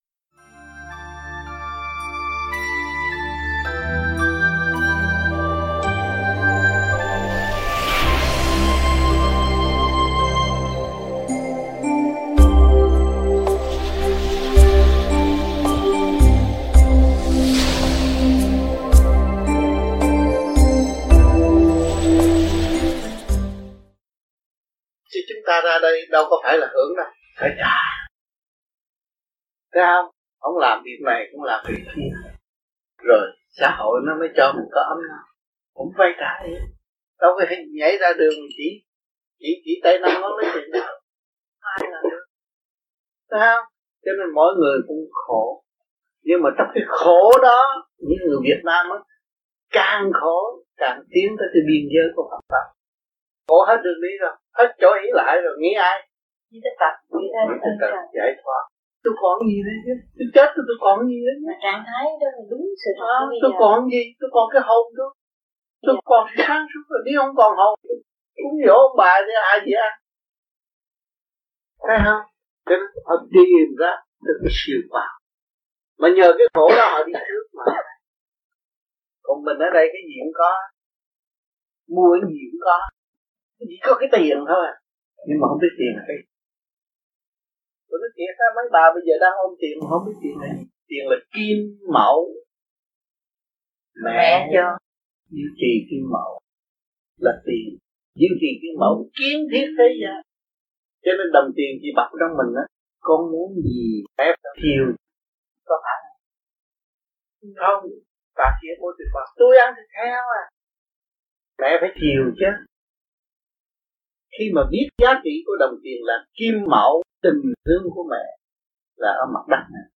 THUYẾT GIẢNG
VẤN ĐẠO